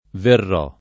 venire v.; vengo [v$jgo], vieni [vL$ni], viene [vL$ne], veniamo [venL#mo], venite [ven&te], vengono [v$jgono] — fut. verrò [